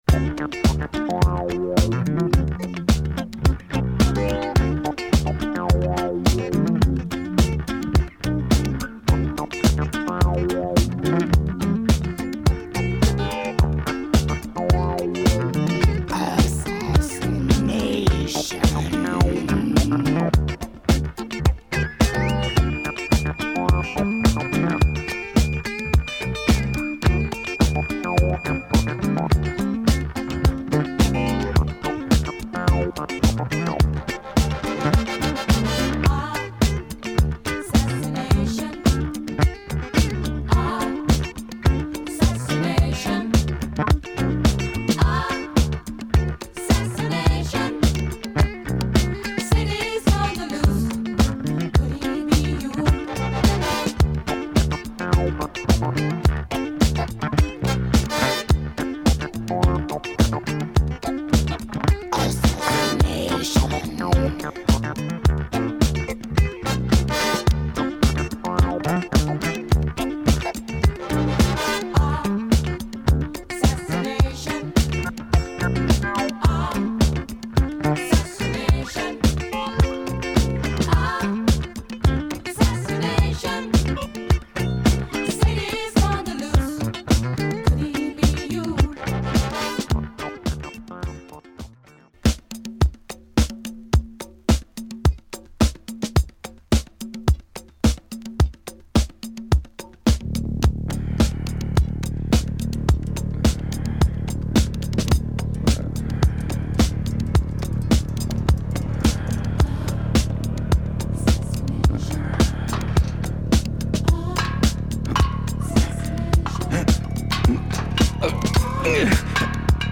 amazingly funky